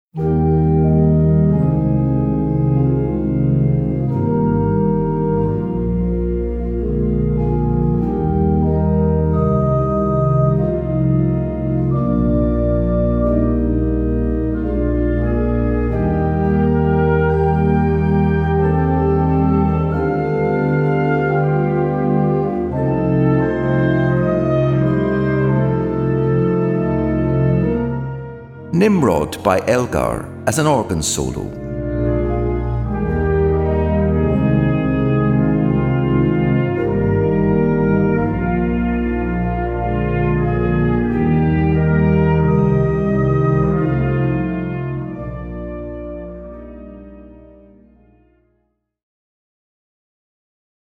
Solo für Orgel und Blasorchester
Besetzung: Blasorchester